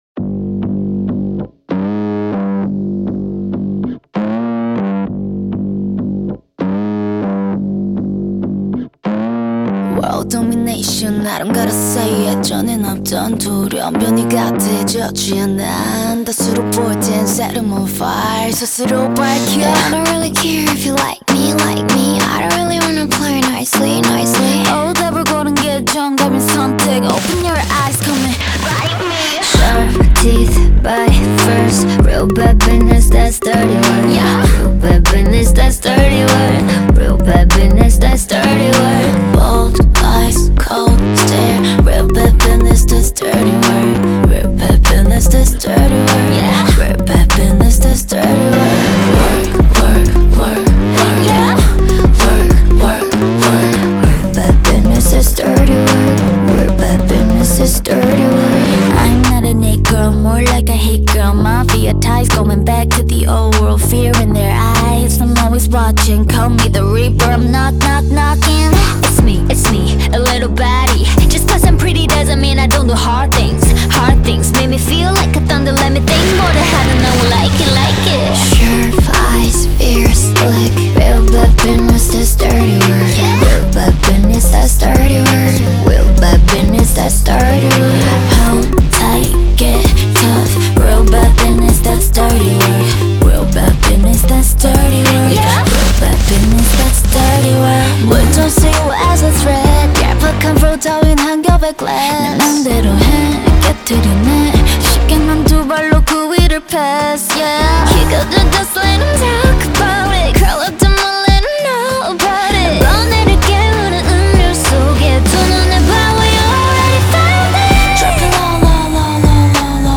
BPM98-98
Audio QualityPerfect (High Quality)
K-Pop song for StepMania, ITGmania, Project Outfox
Full Length Song (not arcade length cut)